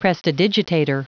Prononciation du mot prestidigitator en anglais (fichier audio)
Prononciation du mot : prestidigitator